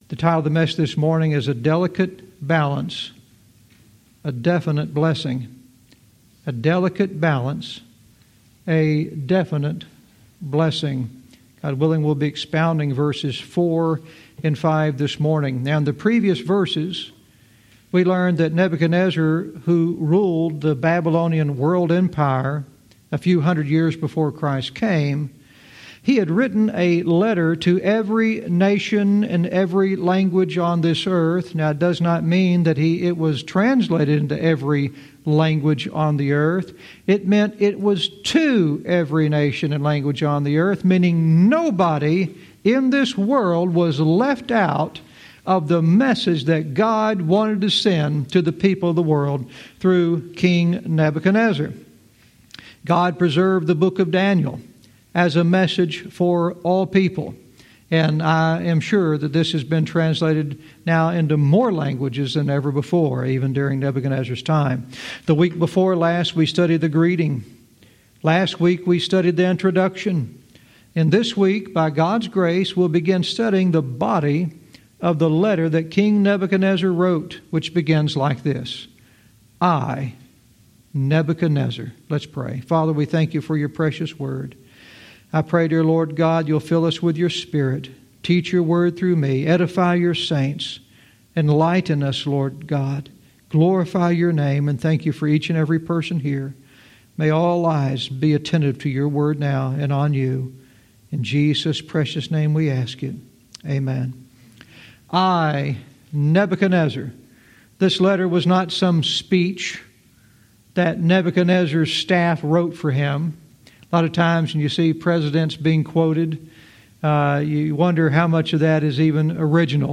Verse by verse teaching - Daniel 4:4-5 " A Delicate Balance, A Definite Blessing"